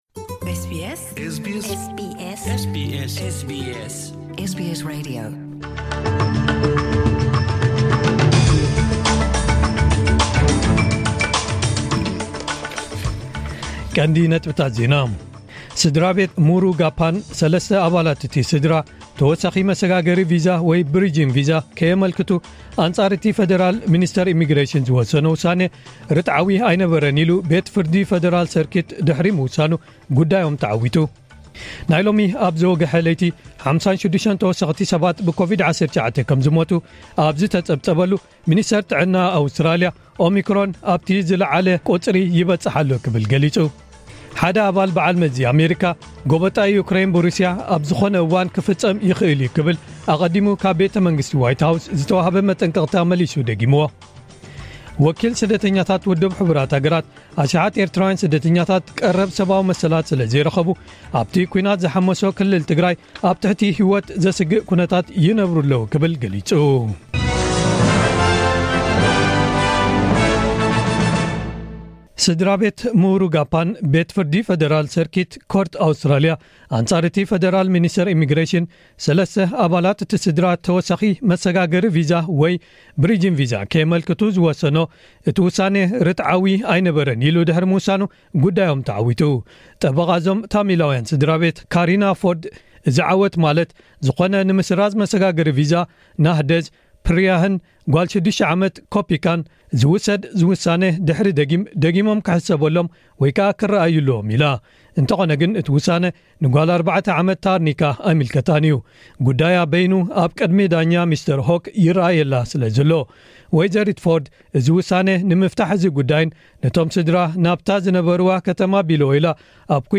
ዕለታዊ ዜና